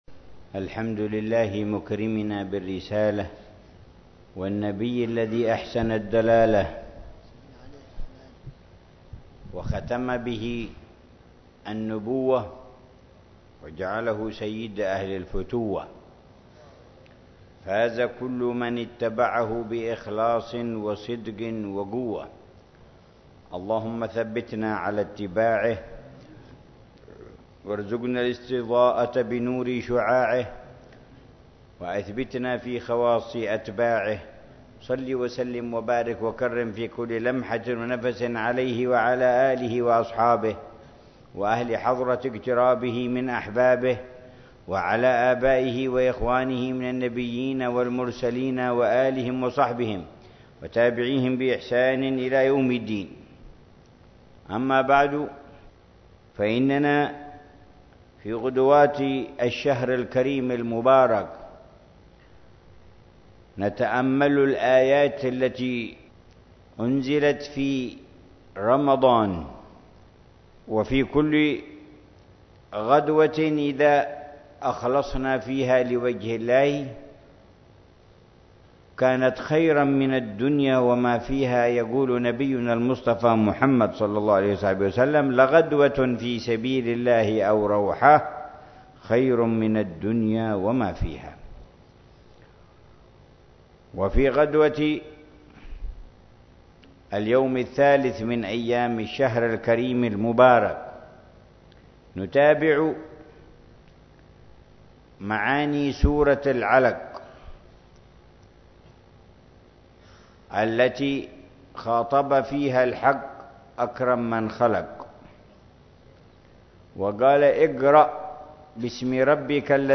يواصل الحبيب عمر بن حفيظ تفسير سورة العلق، موضحا معاني الكلمات ومدلولاتها والدروس المستفادة من الآيات الكريمة، ضمن دروسه الرمضانية في تفسير جز